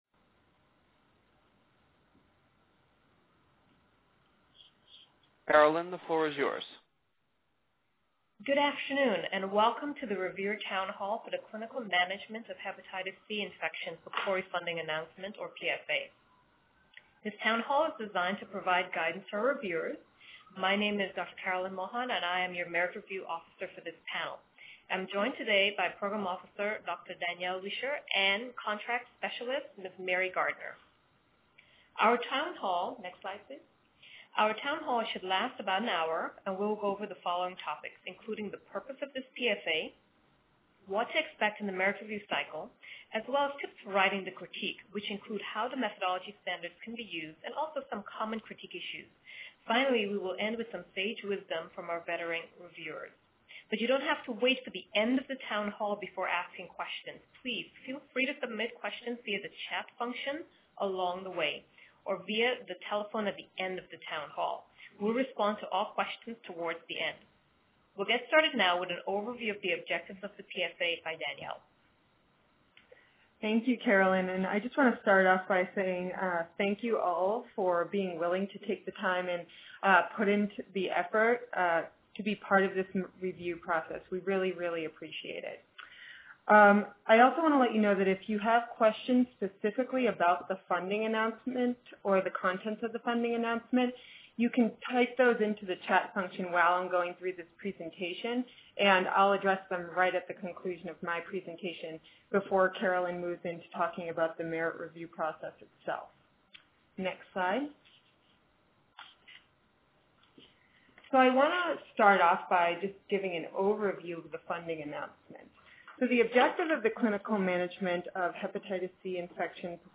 PCORI hosted two concurrent multi-stakeholder workshops to discuss whether comparative clinical effectiveness research (CER) can help to answer questions surrounding long-term use of opioids. We asked representatives of a wide range of stakeholder groups, including patients, caregivers, clinicians, industry, payers, and researchers to participate in this discussion.